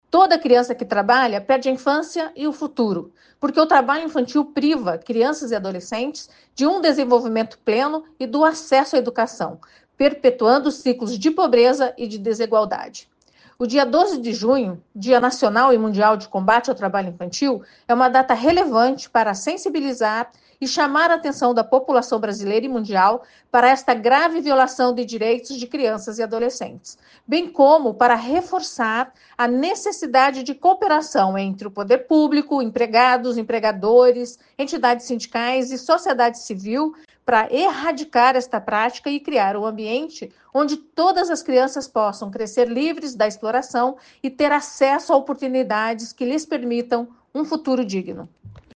A procuradora regional do Trabalho no Paraná, Margaret Matos de Carvalho, destaca a necessidade de combater esse tipo de irregularidade.